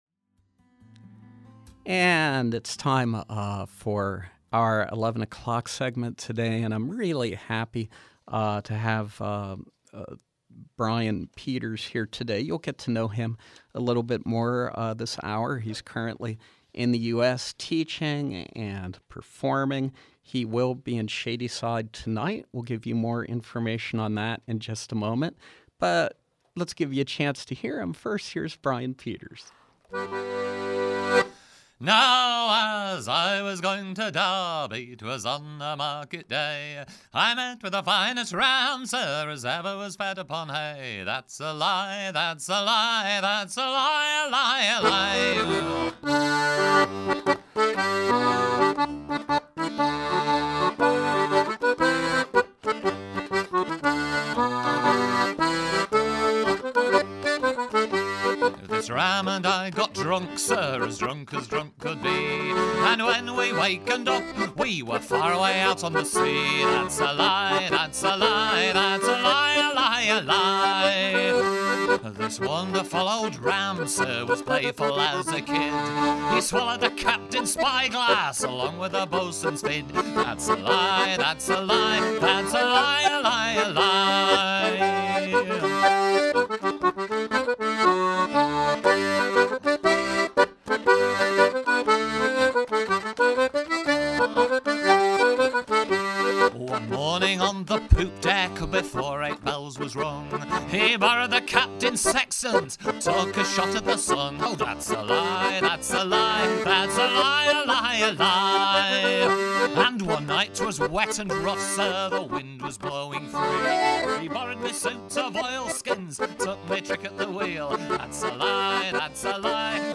British traditional singer, guitarist and squeezebox player
performing live